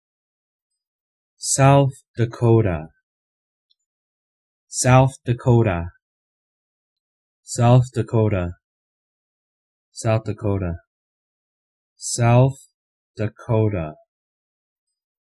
Ääntäminen
Ääntäminen US US : IPA : /ˈsaʊθ dəˈkoʊ.tə/ Lyhenteet ja supistumat S.Dak. Haettu sana löytyi näillä lähdekielillä: englanti Käännös Erisnimet 1.